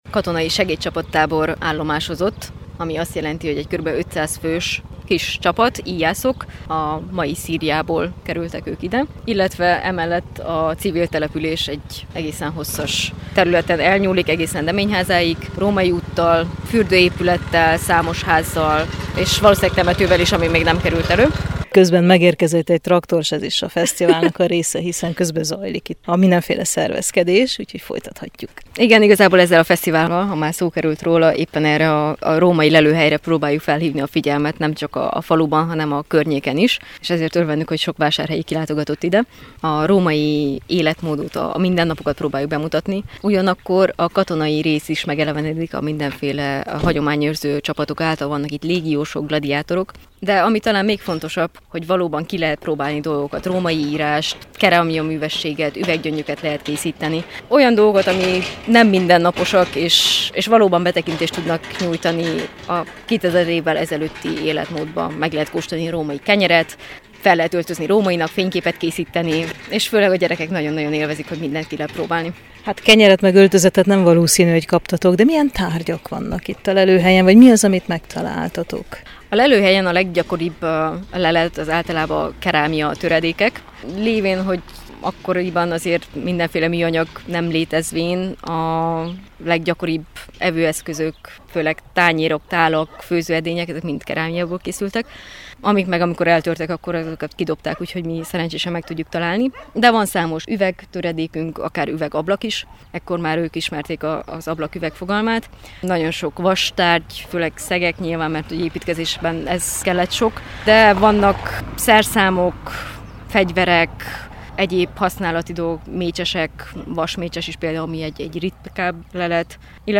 régésszel beszélgettünk arról, hogy mi található a lelőhelyen.